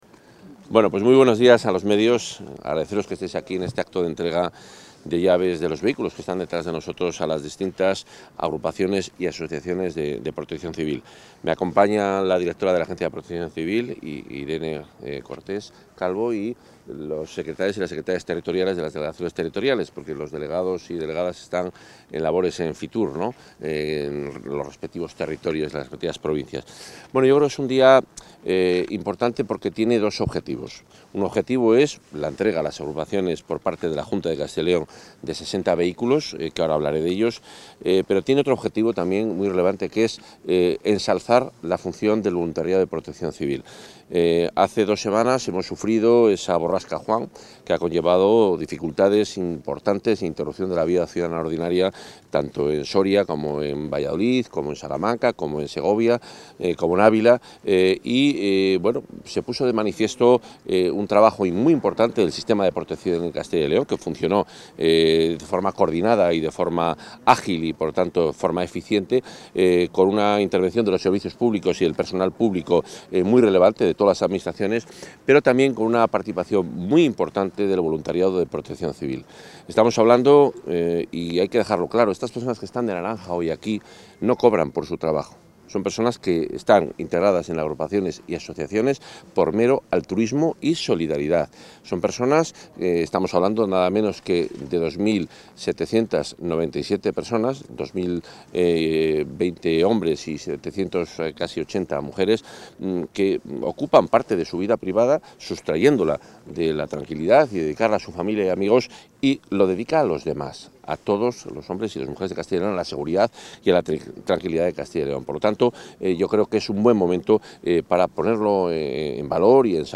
Intervención del consejero.
El consejero de Medio Ambiente, Vivienda y Ordenación del Territorio, Juan Carlos Suárez-Quiñones, acompañado por la directora de la Agencia de Protección Civil y Emergencias, Irene Cortés, representantes de entidades locales y presidentes de asociaciones y agrupaciones de Protección Civil de la Comunidad ha hecho entrega en el parking de la Feria de Muestras de Valladolid de 60 vehículos pick-up.